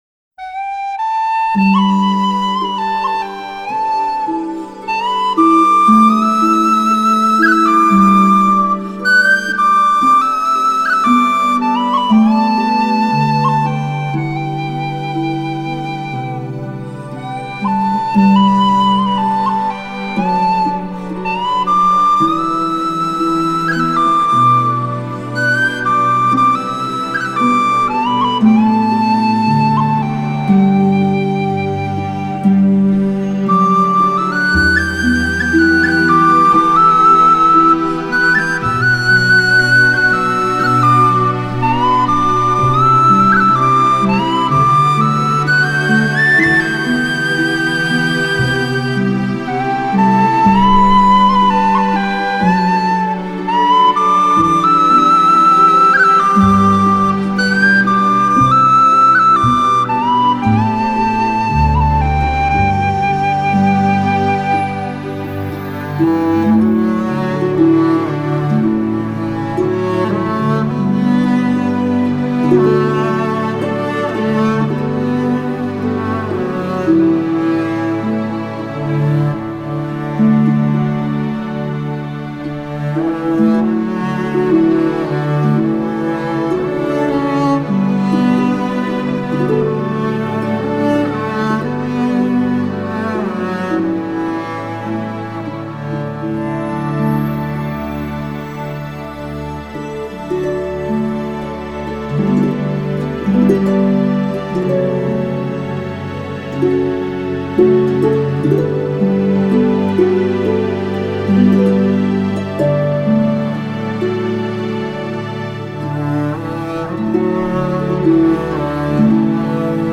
喜欢的4首爱尔兰风笛曲